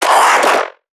NPC_Creatures_Vocalisations_Infected [26].wav